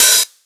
011_Hi-Hat_Open_HardBassHouse.wav